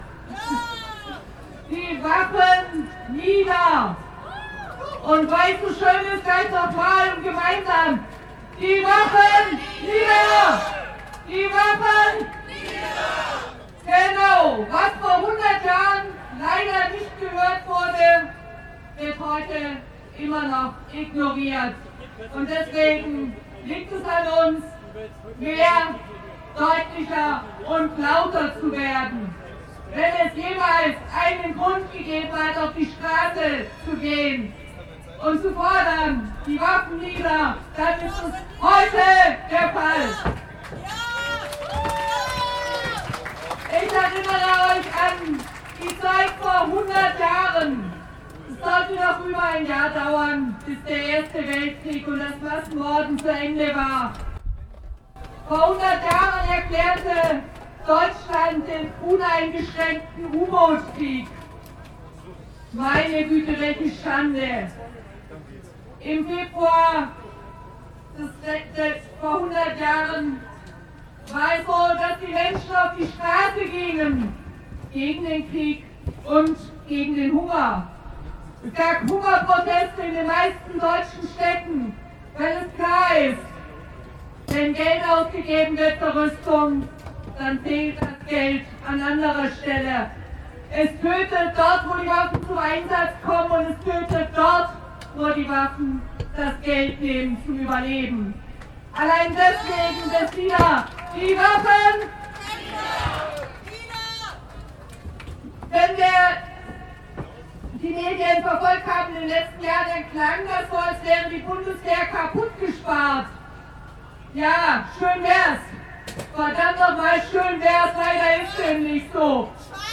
- Rede anhören (MP3: ohton)
Ostermarsch München 2017